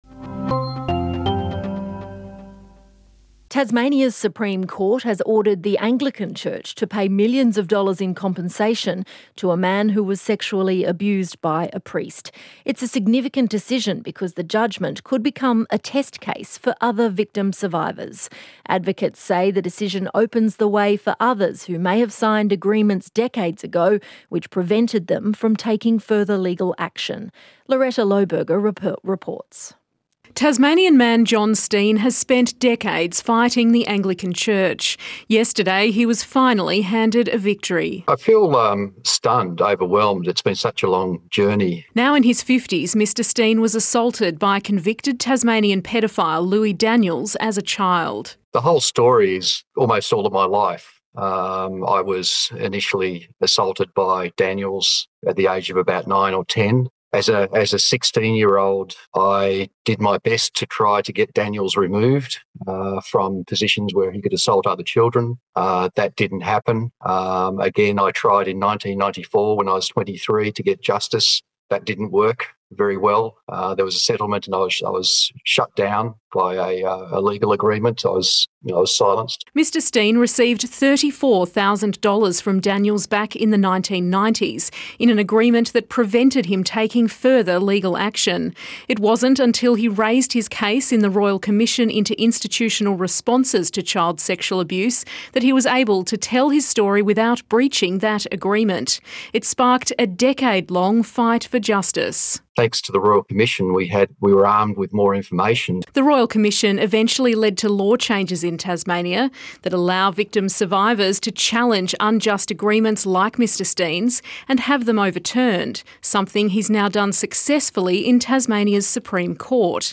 ABC Interview